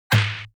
playerKnockback1.wav